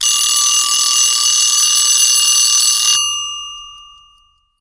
bomb_alarm_02.ogg